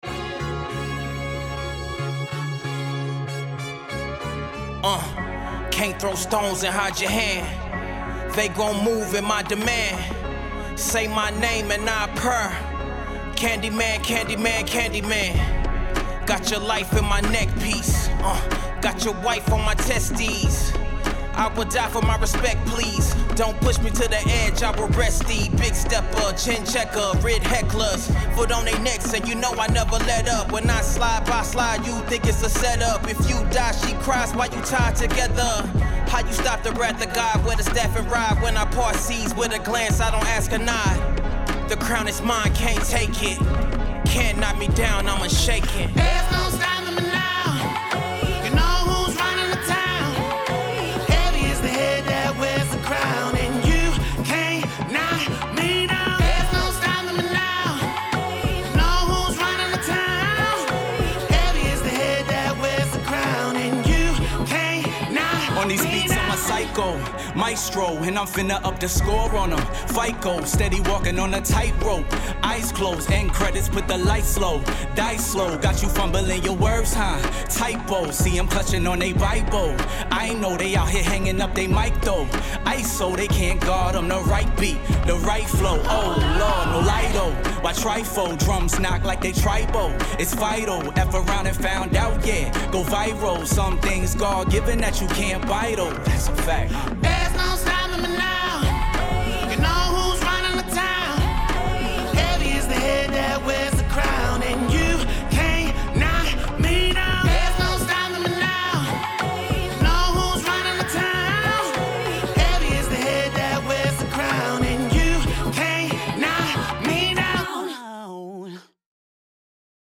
Soul, Hip Hop
F# Minor